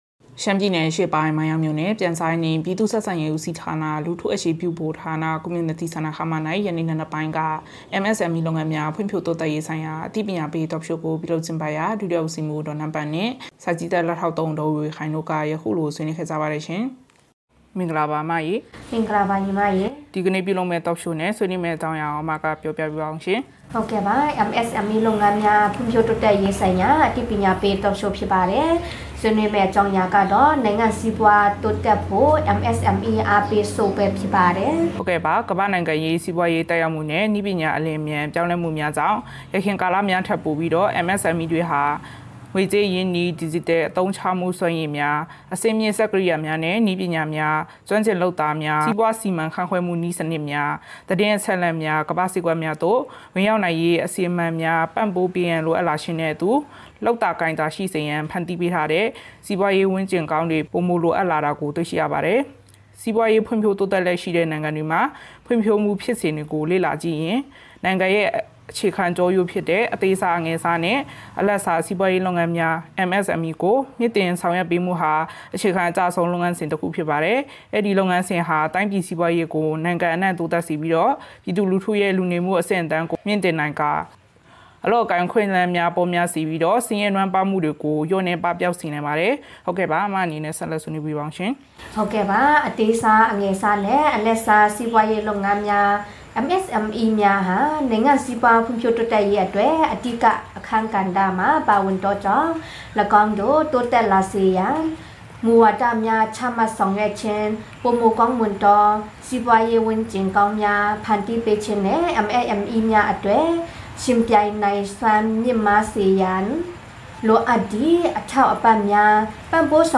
မိုင်းယောင်းမြို့၌ MSMEလုပ်ငန်းများဖွံ့ဖြိုးတိုးတက်ရေးဆိုင်ရာအသိပညာပေးTalk Show ပြုလုပ် မိုင်းယောင်း စက်တင်ဘာ ၉